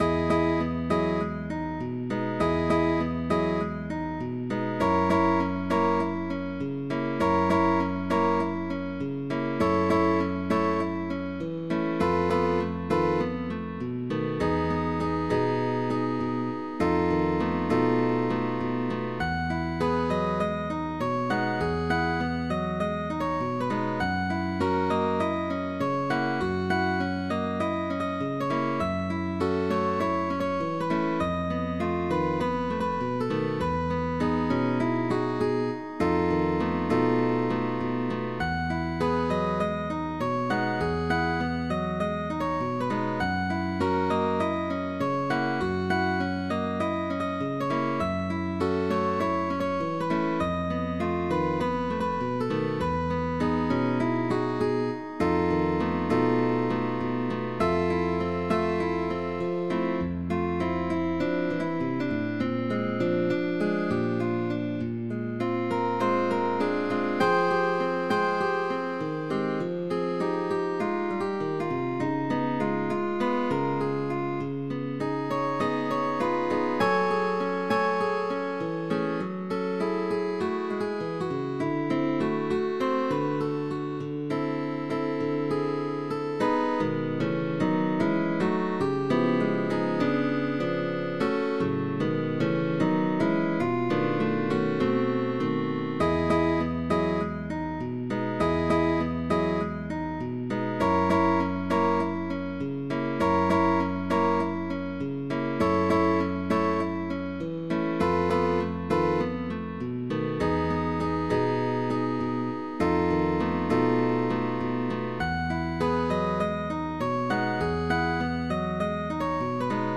es una canción de bossa nova compuesta en 1962
Ligados, arpegios, cejillas, posiciones altas, acordes,…